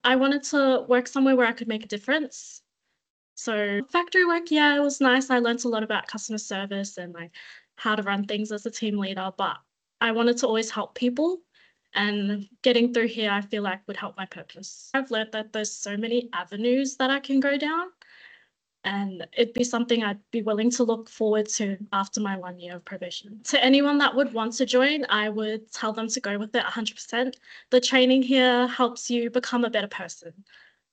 Audio grabs below from QCS CCOs and Dog Squad Officers below: